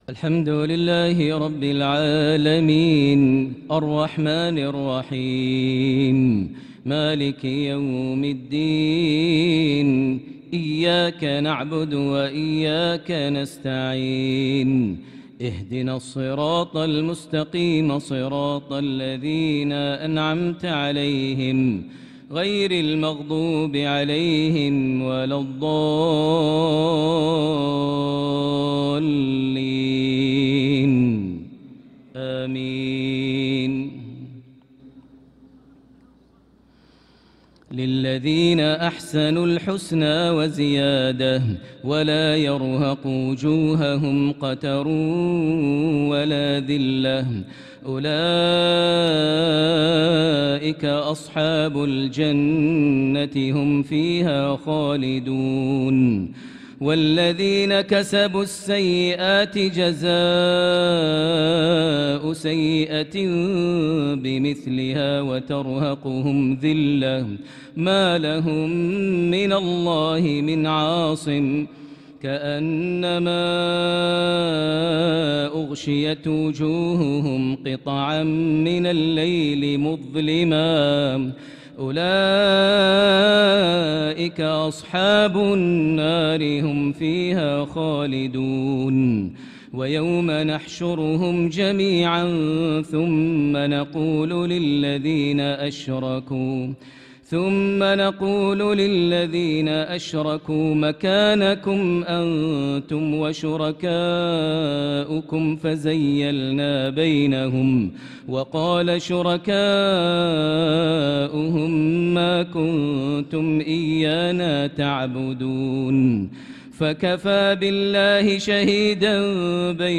صلاة العشاء للقارئ ماهر المعيقلي 10 ذو القعدة 1445 هـ
تِلَاوَات الْحَرَمَيْن .